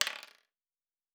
Dice Single 5.wav